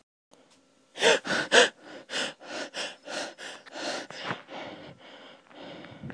日常的声音" 喘息缓慢
描述：喘息
Tag: 喘气 用人力 呼吸 呼吸 空气 吸气 呼吸